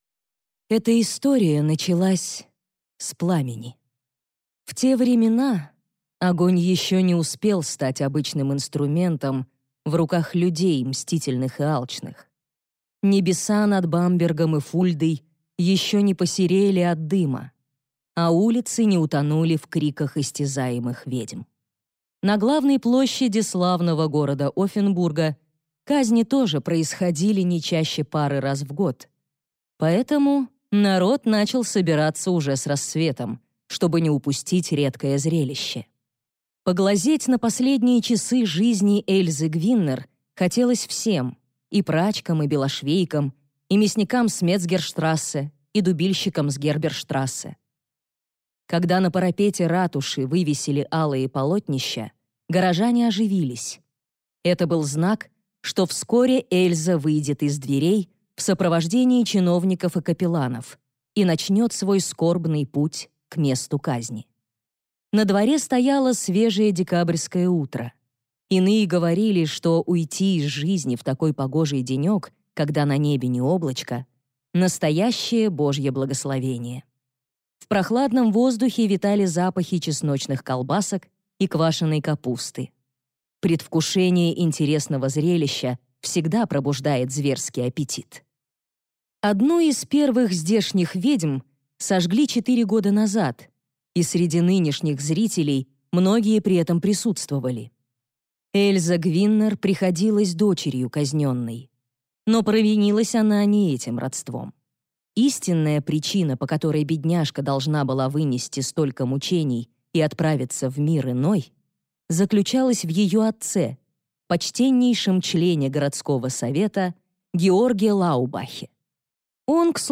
Аудиокнига Хозяйка Шварцвальда | Библиотека аудиокниг